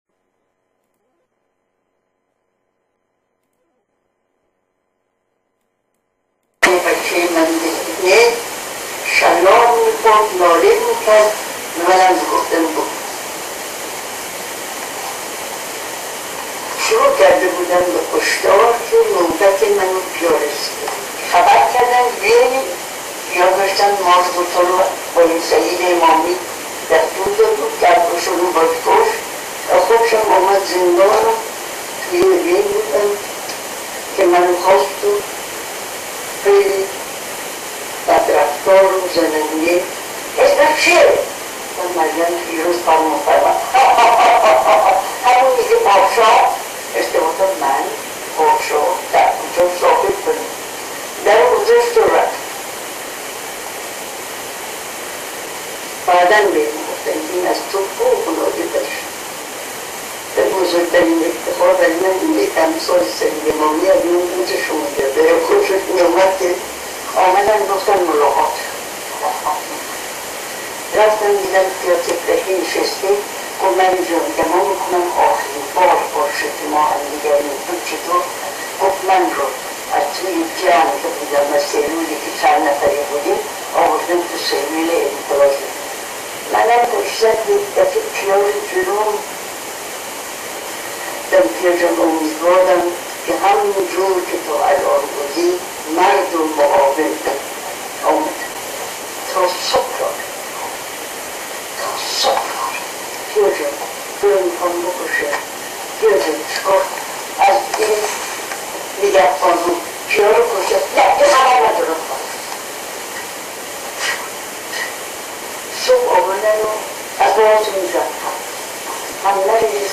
فیلم مستندی که اجازه نمایش نیافته!
یکی از کسانی که در سالن نمایش این فیلم حضور داشته، با تلفن همراه خویش توانسته بخش هائی از فیلم را ضبط کند، که بدنبال تذکری که به وی داده شده، ادامه فیلمبرداری ممکن نشده است.
کیفیت صدا و فیلم به دلیل شرایطی که در بالا به آن اشاره شد نازل تر از اصل فیلم و صداست، بویژه که ما نیز مجبور شدیم برای سهولت دیدن و شنیدن، از حجم آن ها کم کنیم.